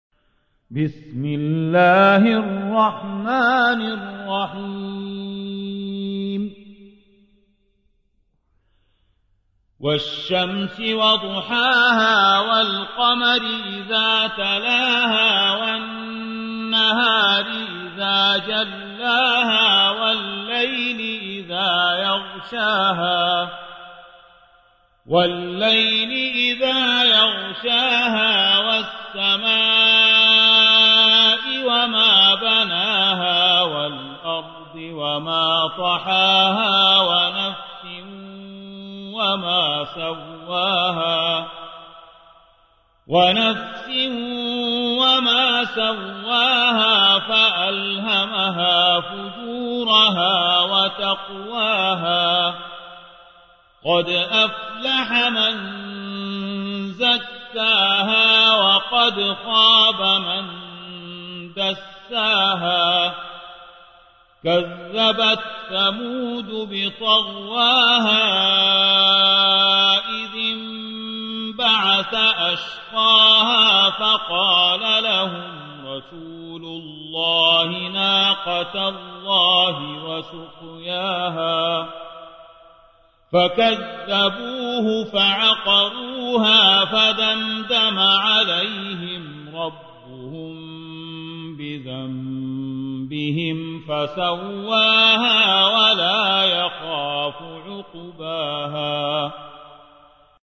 91. سورة الشمس / القارئ